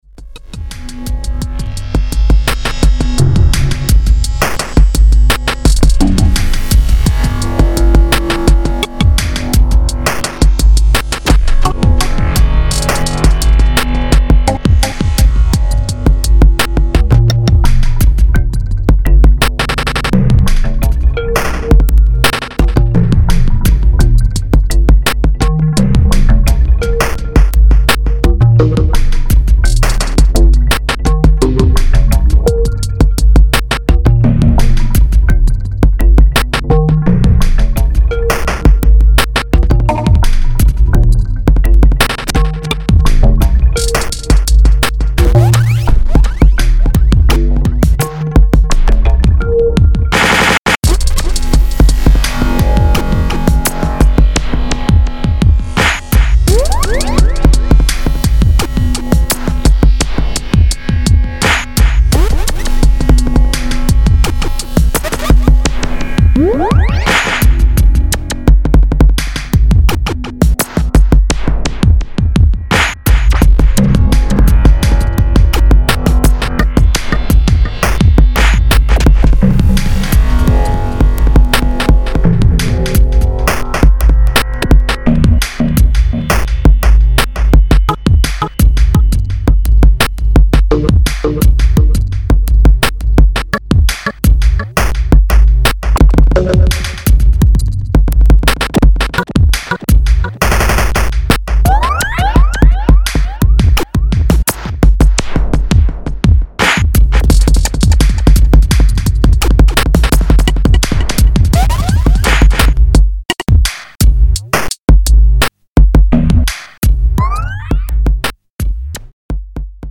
Electro Electronix